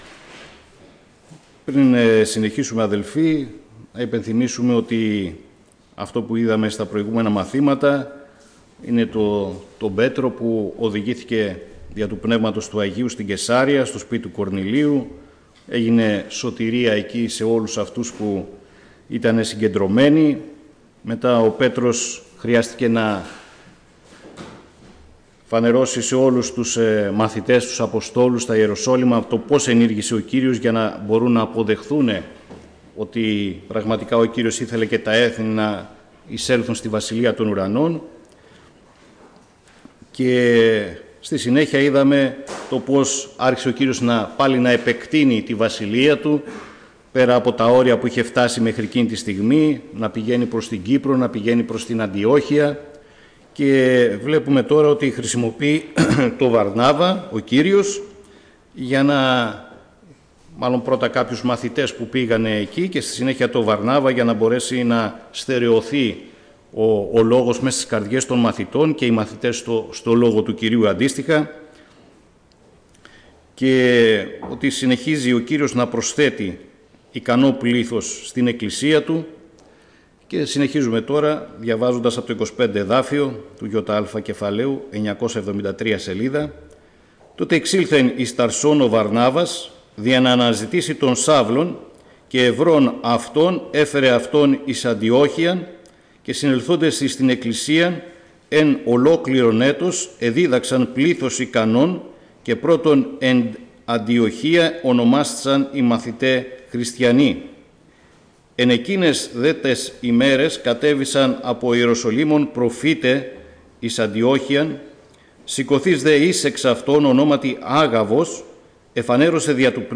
Ομιλητής: Διάφοροι Ομιλητές